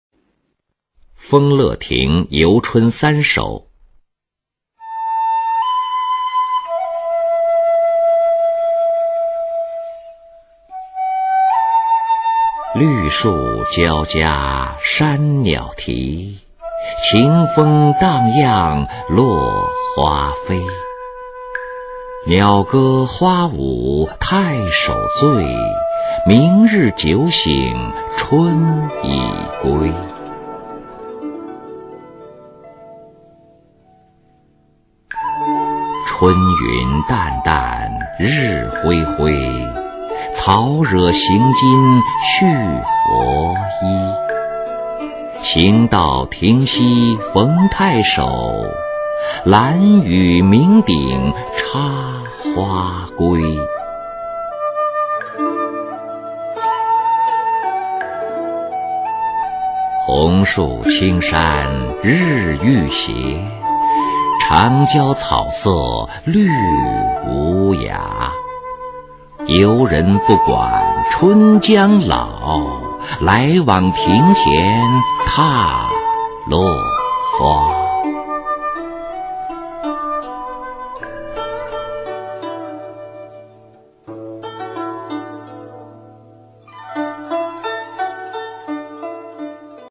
[宋代诗词朗诵]欧阳修-丰乐亭游春三首 古诗词诵读